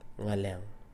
Pronunciation
ngal-eng
For those of you who know IPA (International Phonetic Alphabet): [ŋaleŋ]